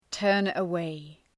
turn-away.mp3